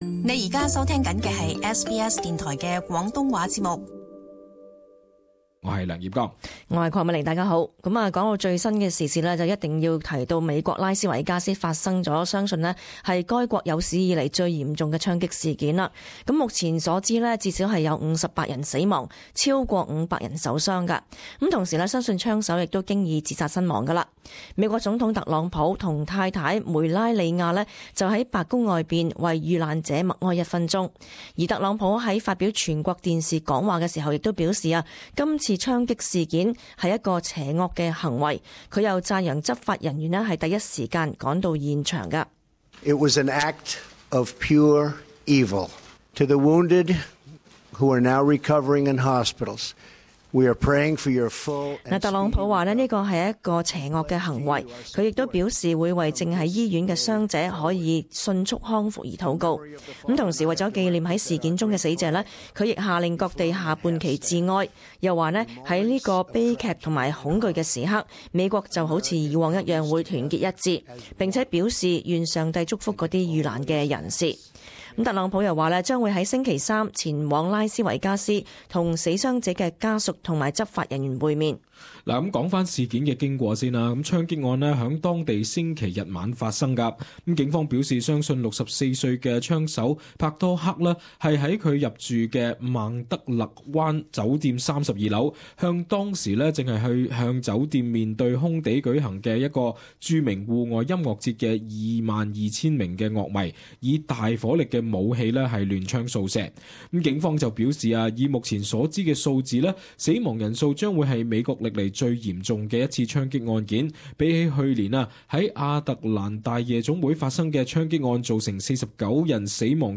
【時事報導】拉斯維加斯發生美國史上最嚴重槍擊案